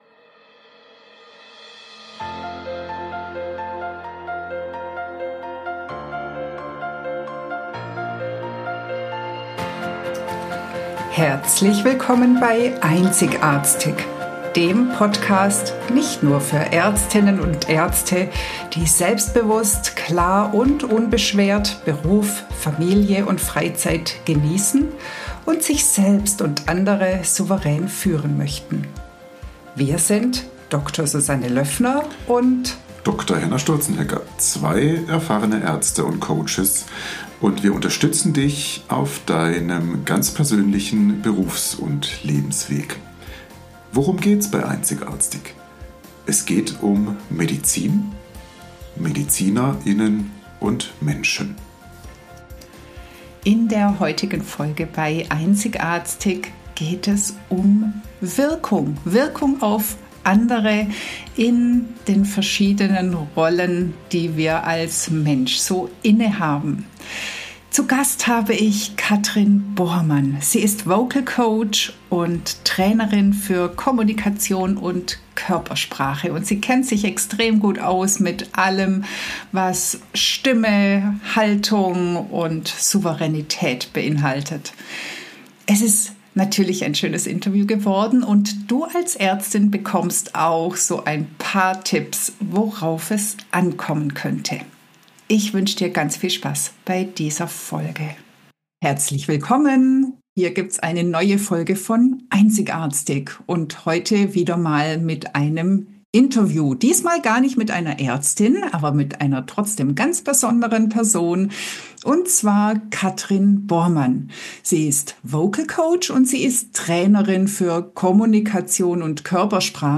#109 Wie ist deine Wirkung? Interview